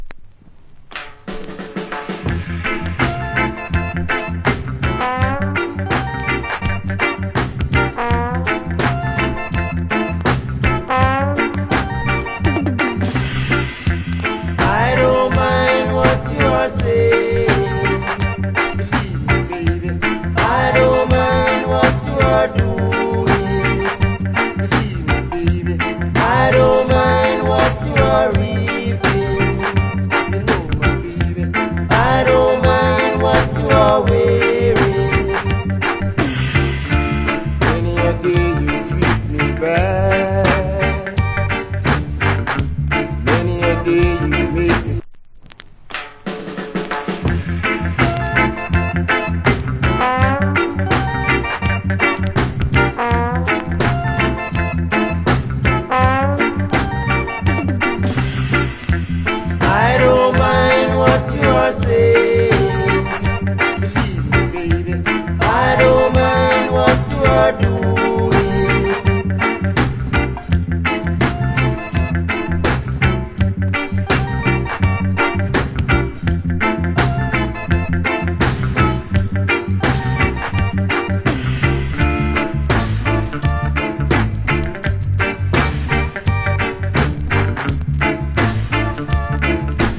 Rock Steady Vocal. 80's Remix.